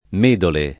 Medole [ m % dole ]